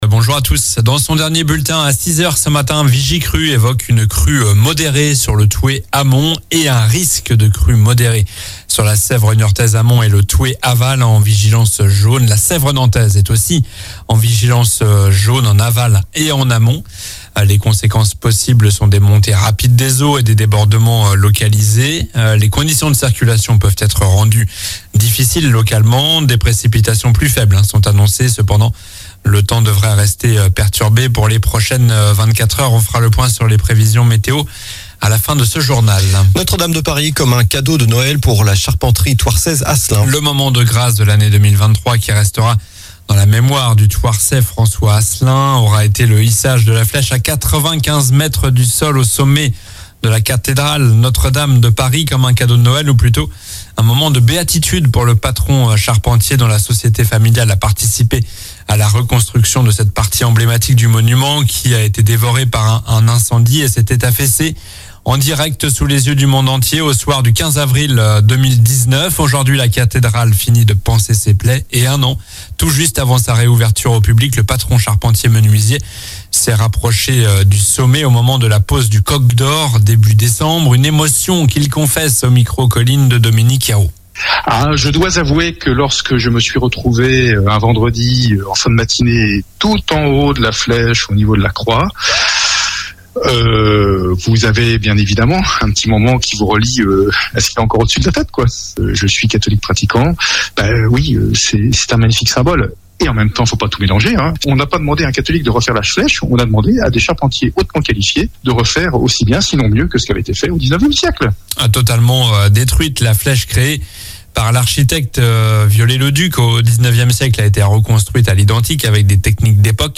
Journal du mercredi 03 janvier (matin)